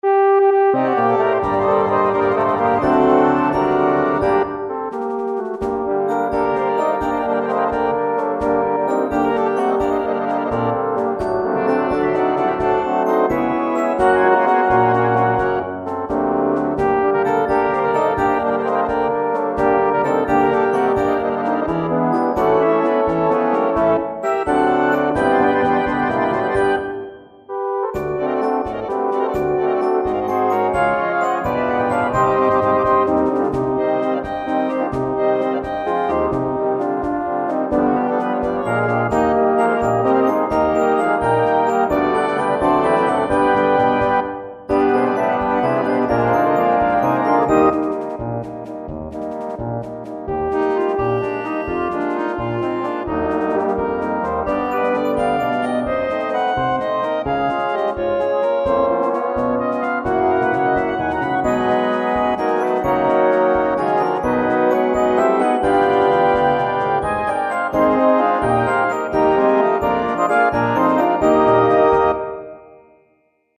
Categorie Harmonie/Fanfare/Brass-orkest
Subcategorie Concertmars
Bezetting Ha (harmonieorkest)